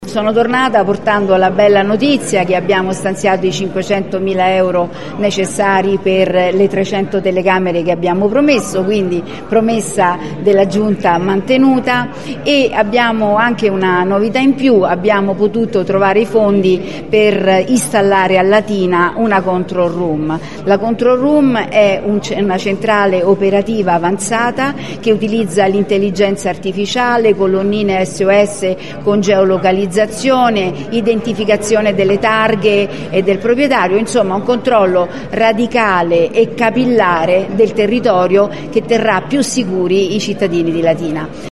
Della prossima installazione di una Control Room a Latina ha parlato invece, a margine di un incontro pubblico di Forza Italia che si è tenuto nei giorni scorsi a Latina, l’assessora regionale Regimenti